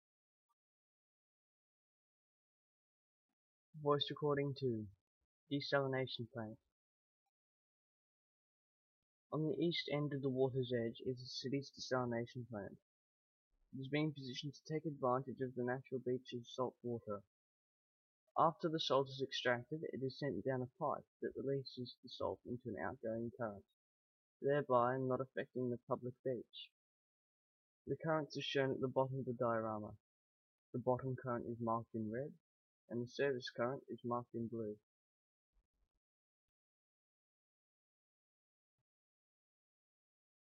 Desalination Plant